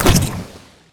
lifeimpact03.wav